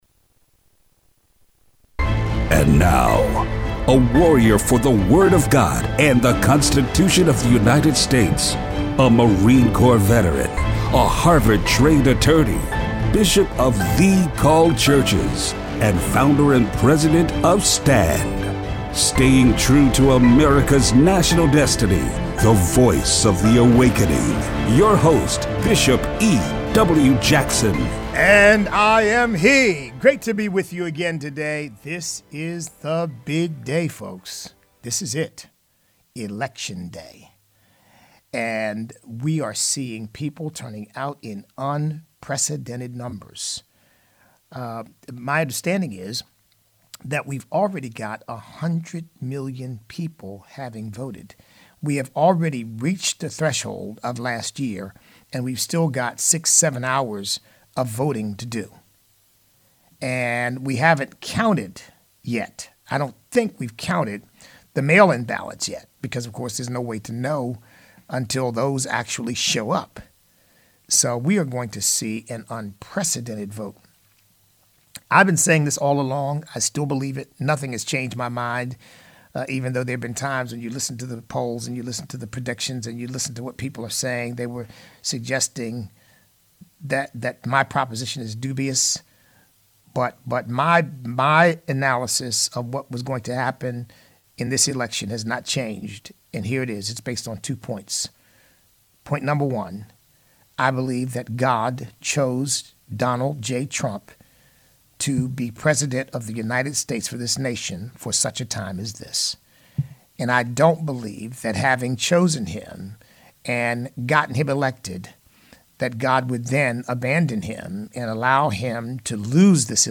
Show Notes We hear from our listeners about their voting experiences.